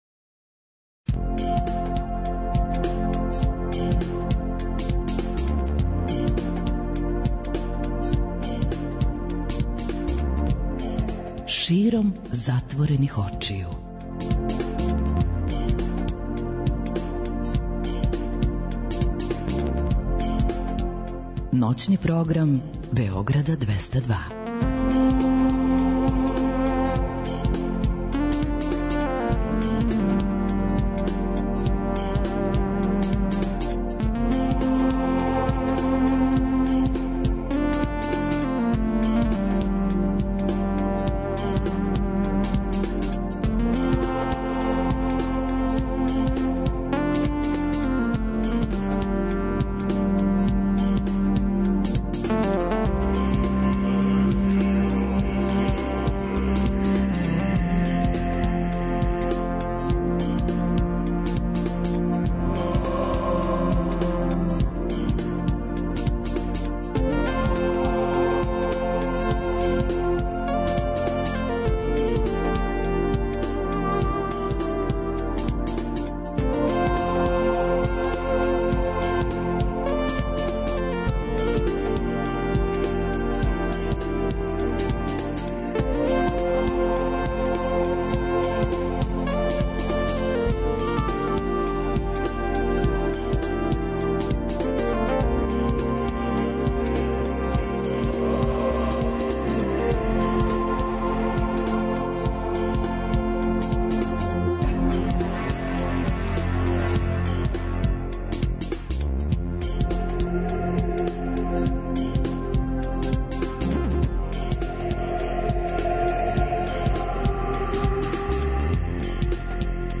Ноћни програм Београда 202.
Тако некако изгледа и колажни контакт програм "САМО СРЦЕМ СЕ ДОБРО ВИДИ".